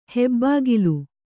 ♪ hebbāgilu